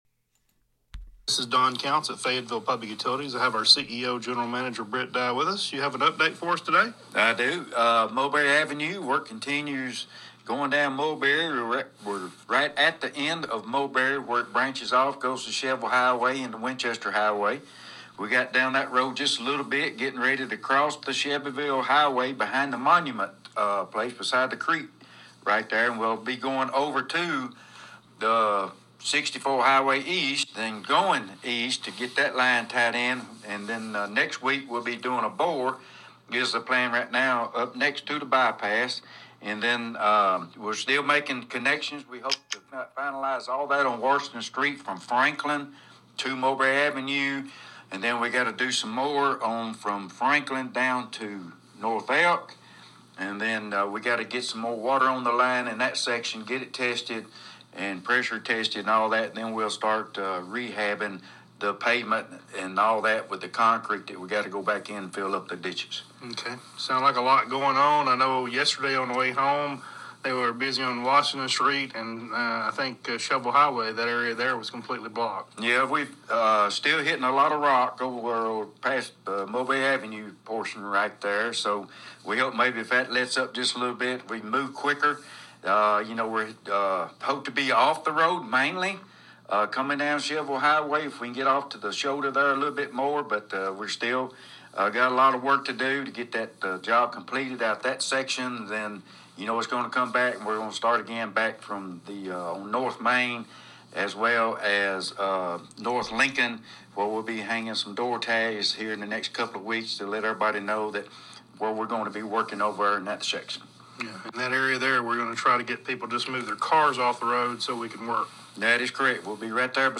You can hear the complete interview on our website 1055WYTM, look under Local News then Water Infrastructure Update,  and click the audio link.